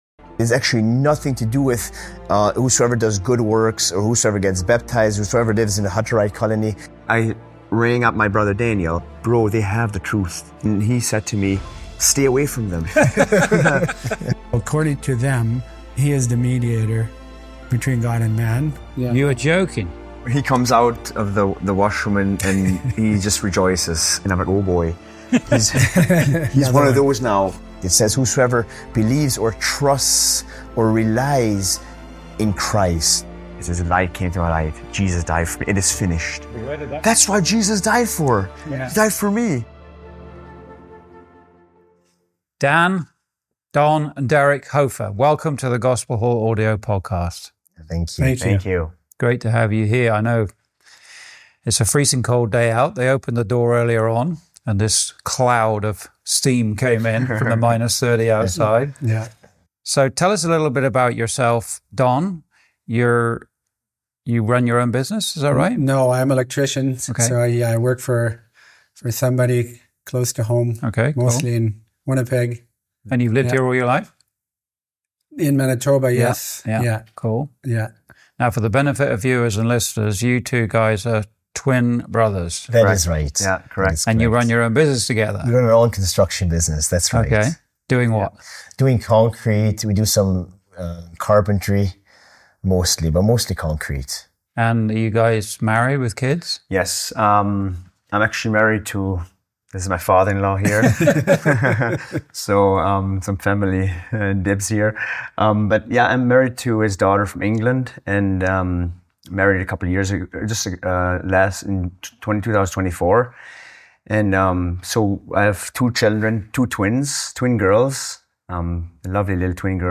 Testimonies
Recorded in Manitoba, Canada, 24th Feb 2026